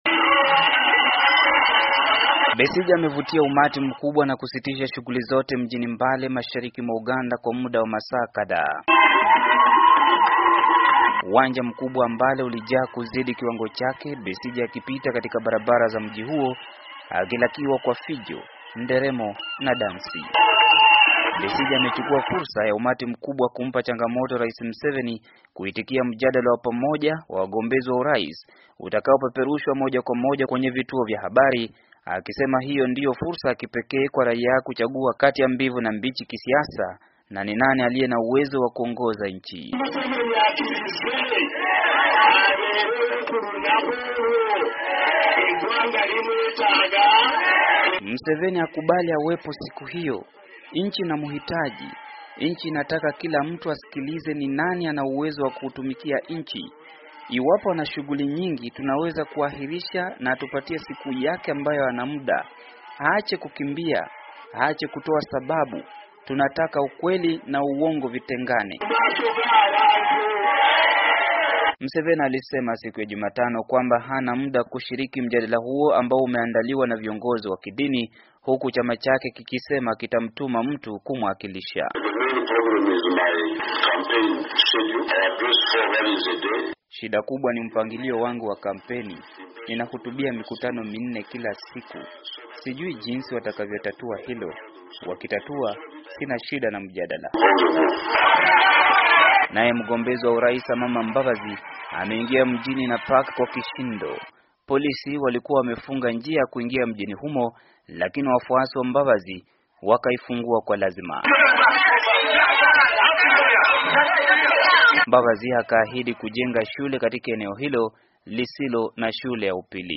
Besigye alikuwa akihutubia umati mkubwa wa watu mashariki mwa Uganda katika mji wa Mbale akiwa kwenye uwanja mmoja mjini humo uliojaa watu kupita kiasi.